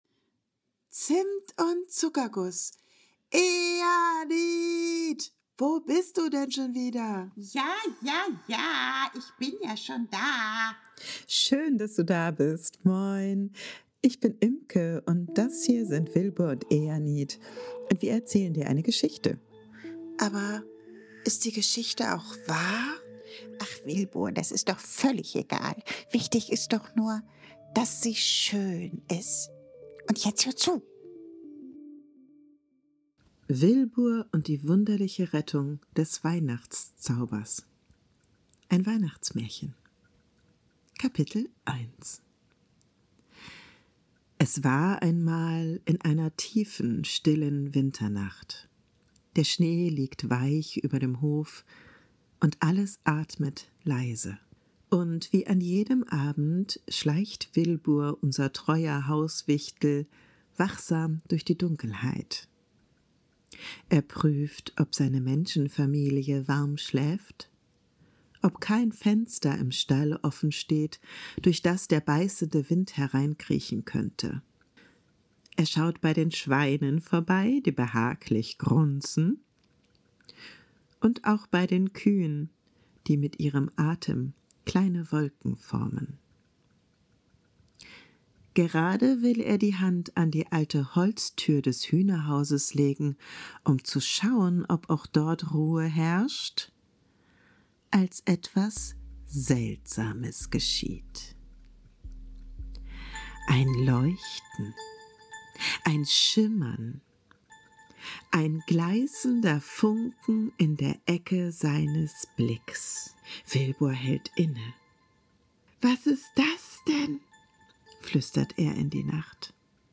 Eine ruhige Weihnachtsgeschichte für Kinder ab 4 Jahren.